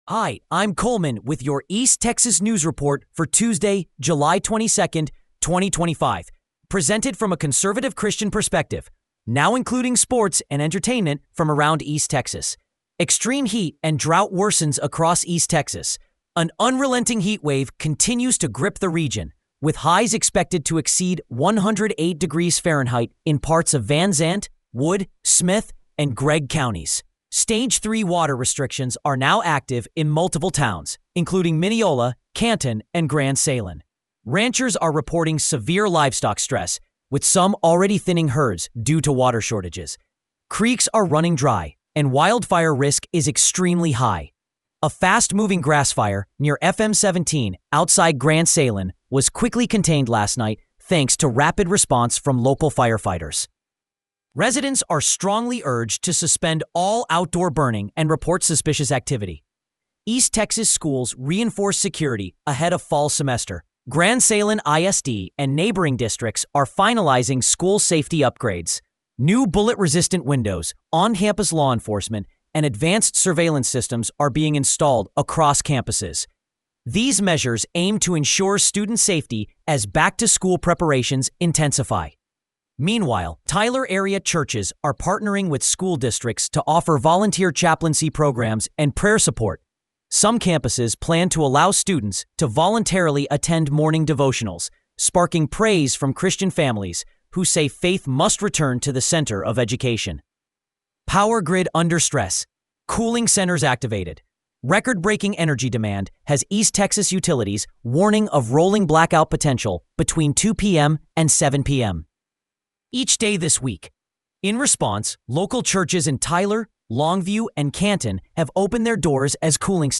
EAST TEXAS NEWS REPORT for Tuesday, July 22, 2025